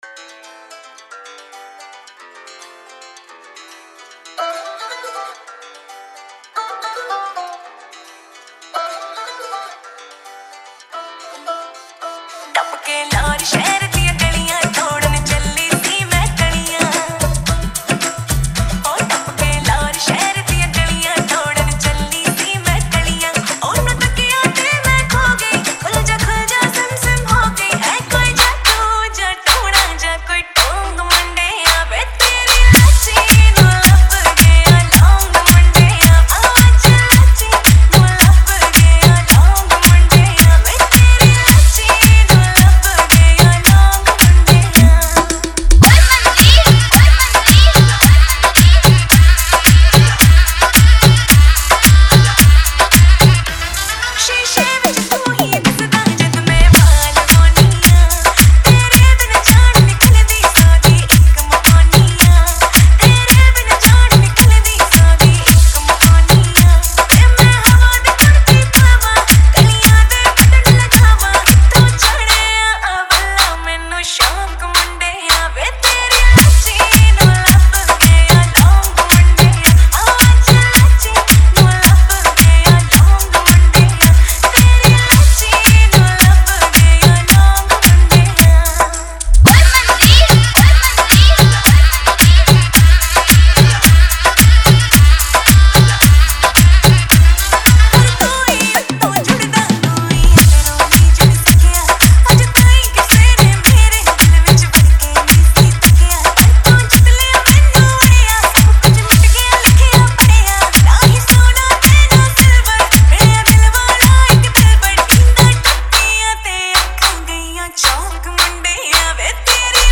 Punjabi Remix